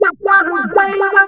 VOX FX 4  -R.wav